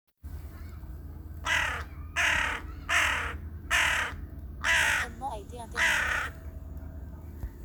Corneille noire, chant I
CorneilleNoire-Chant.mp3